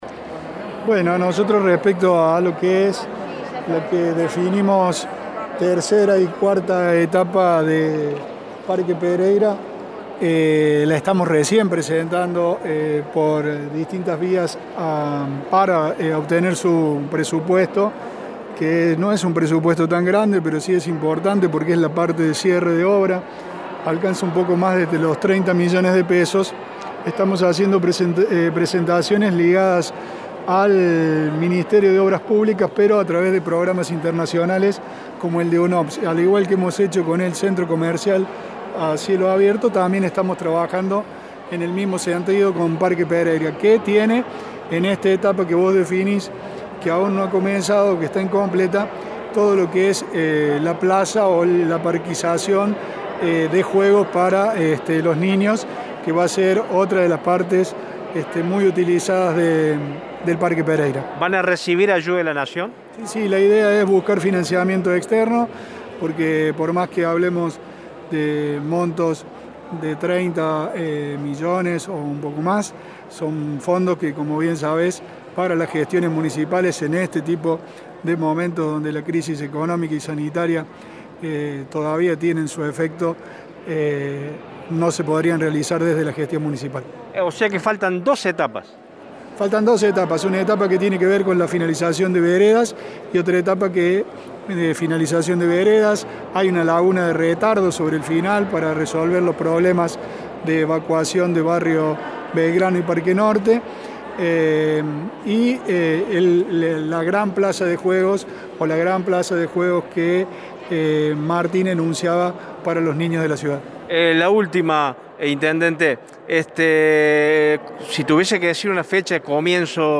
Consultado por Radio Show sobre este tema, el intendente interino Pablo Rosso reconoció que los trabajos que faltan concluir son veredas y una plaza de juego para niños, además de una laguna de retardo.